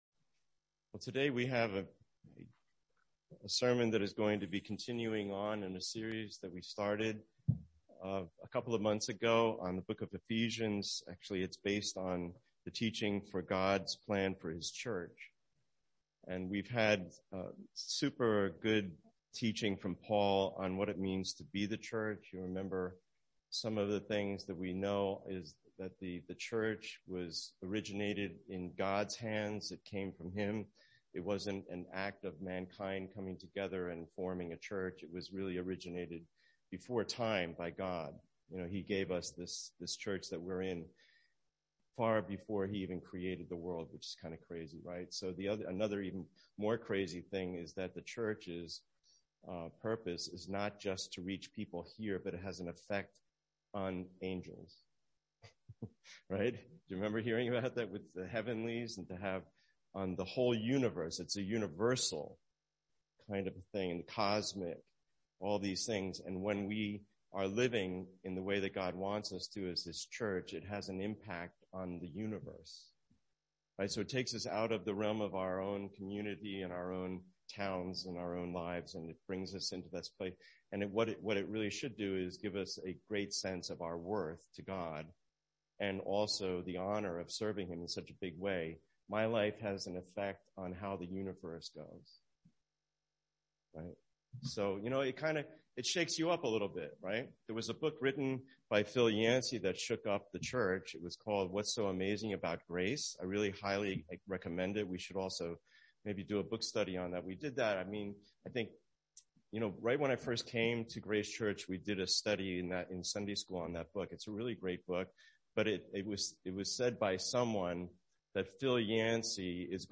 Sermon Series:God’s Plan for His Church #5
Video: Sunday English Worship Video